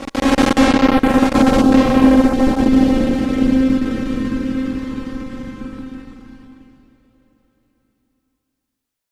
Amb1n2_b_synth_c_distortion1.wav